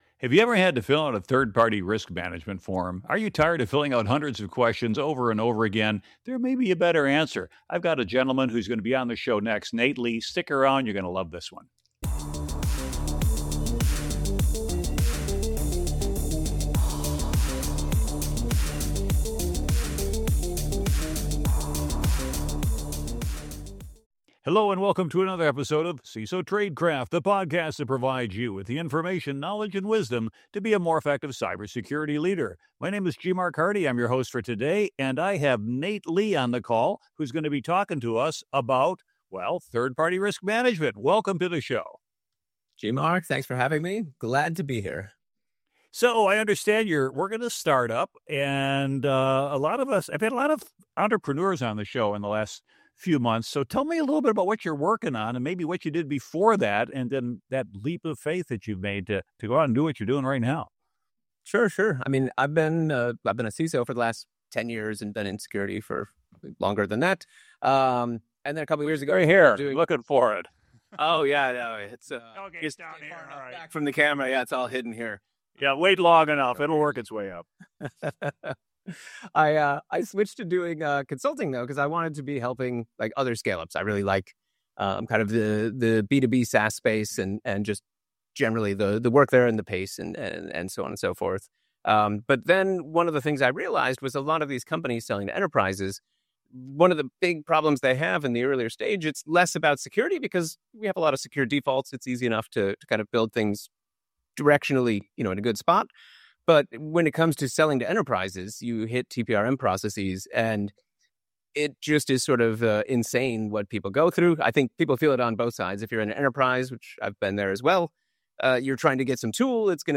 The conversation offers actionable guidance for buyers and vendors to streamline TPRM, focus on real risk, and build stronger, more scalable security programs.